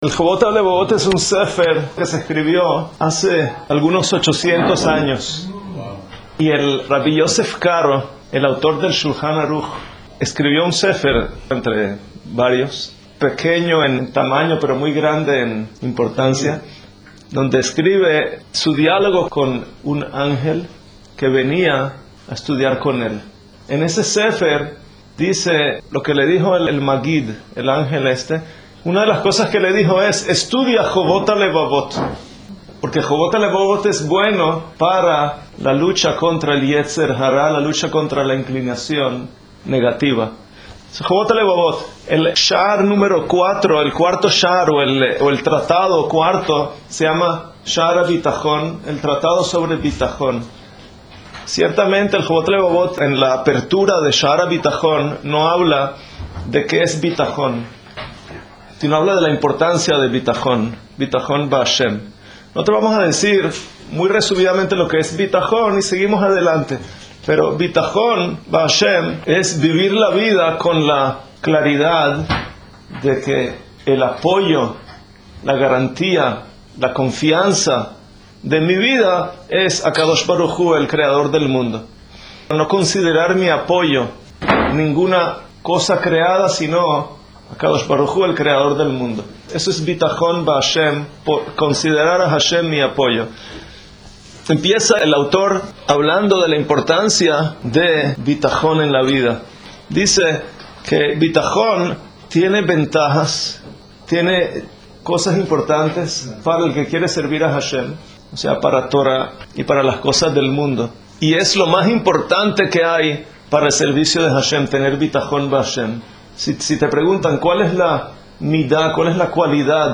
clases de Tora